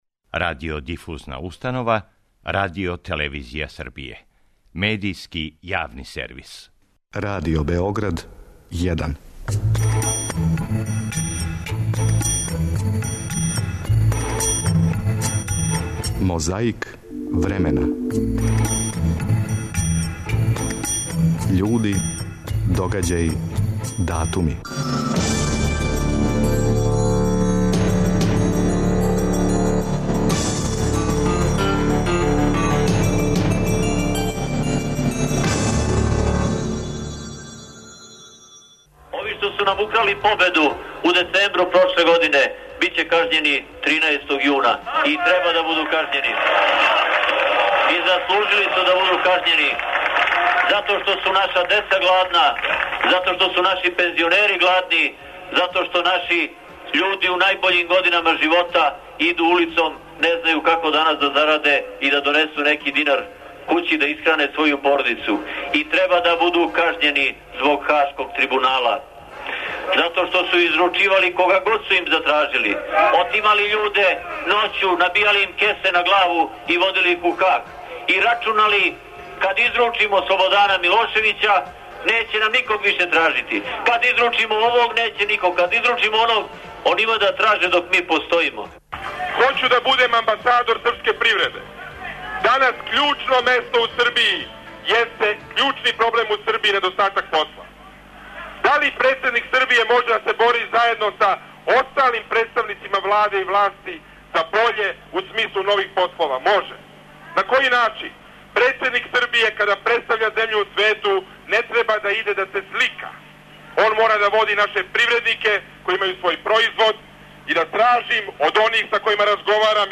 Присетићемо се како су говорила двојица кандидати за председника Србије у предизборној кампањи 2004. године.